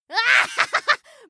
laughter_02